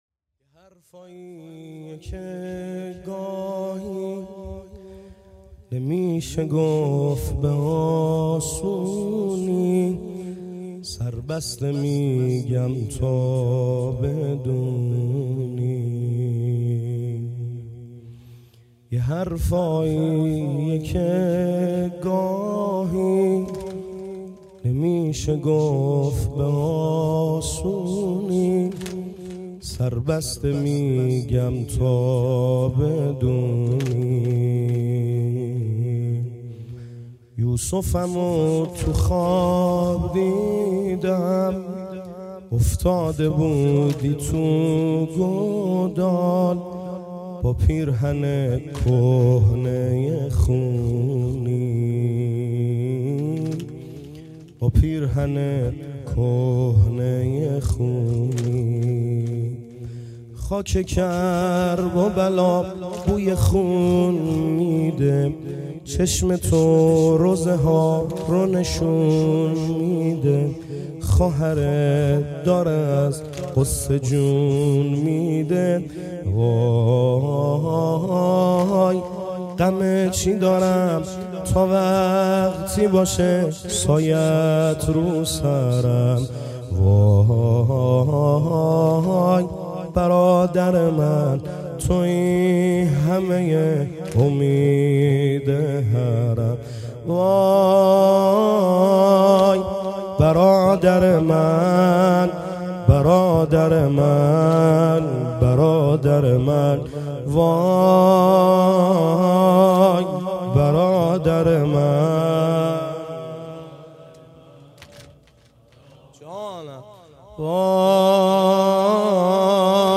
گزارش صوتی شب دوم محرم 98 | هیأت محبان حضرت زهرا سلام الله علیها زاهدان